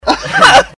Laugh 17